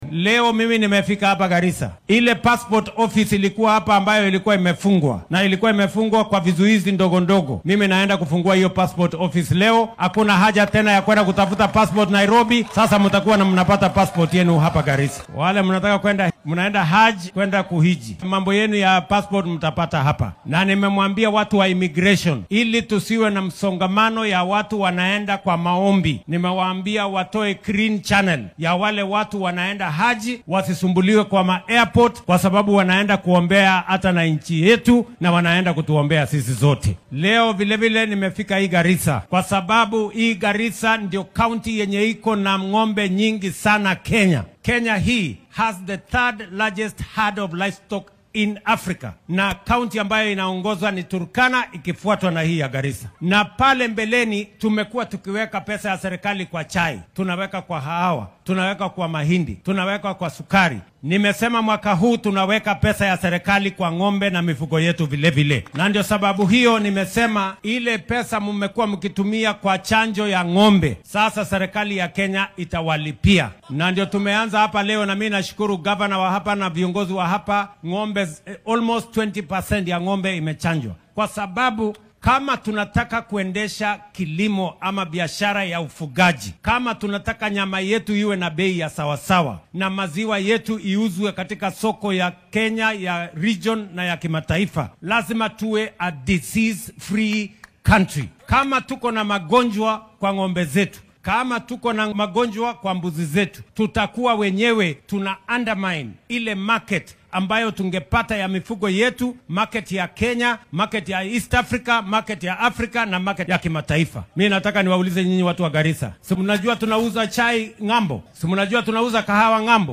Madaxweynaha dalka William Ruto oo maanta socdaal ku tagay ismaamulka Garissa ayaa sheegay inuu dib u furi doono xafiiska laanta socdaalka ee Garissa si loogu adeego shacabka gobolka. Waxaa uu sheegay inuu sidoo kale saraakiisha waaxdan ku amray in howlaha loo fududeeyo dadka doonaya in ay soo gutaan cibaadada xajka. Sidoo kale madaxweynaha dalka ayaa ka hadlay tallaalka xoolaha iyo wanaajinta wax soo saarkooda.